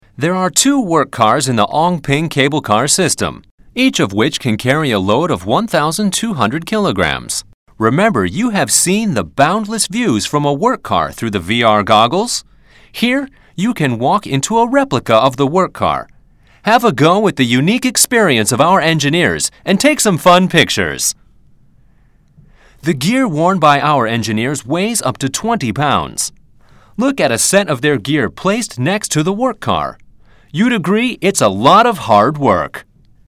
Cable Car Discovery Centre Audio Guide (English)